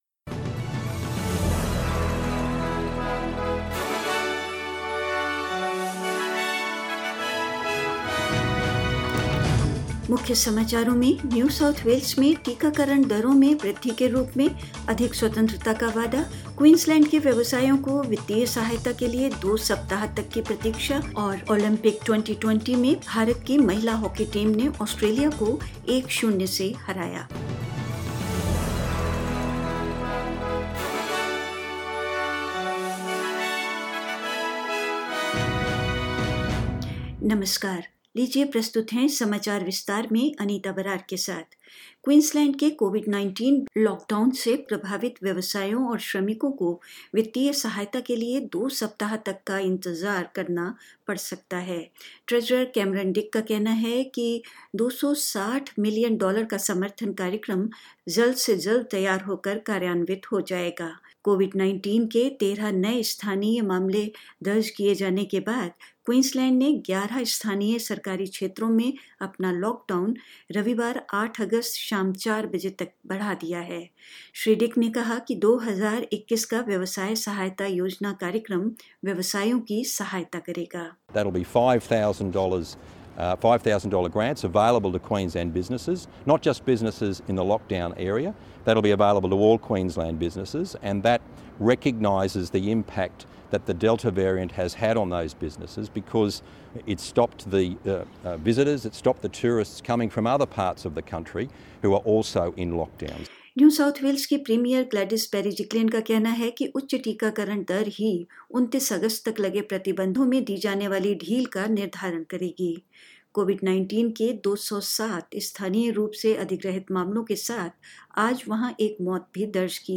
In this latest SBS Hindi News bulletin of Australia and India: Queensland businesses could wait up to two weeks for financial support; A fresh push for vaccination in Sydney as the lockdown continues and the state records 207 new coronavirus cases; Australia defeated by India in women's hockey at the Olympics and more